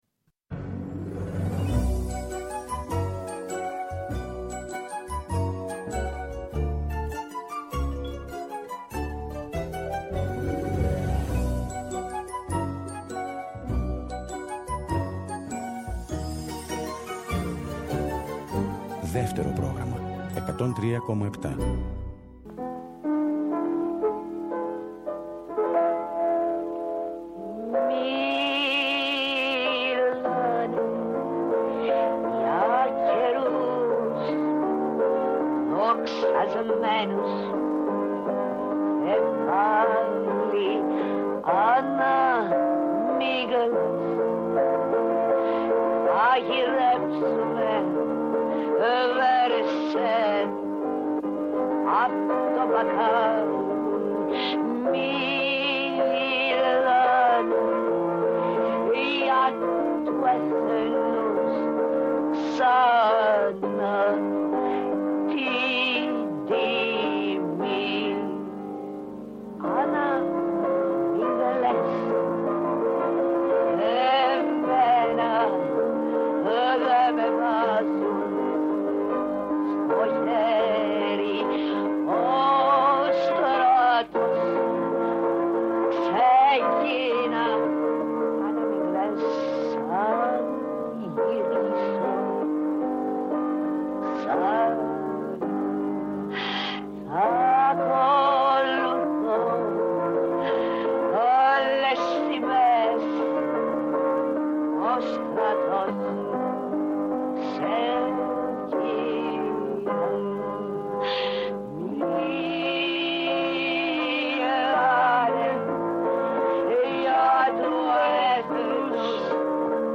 Στην σημερινή μας εκπομπή επιλέγουμε τραγούδια που ερμήνευσαν πρωταγωνίστριες του θεάτρου και του κινηματογράφου.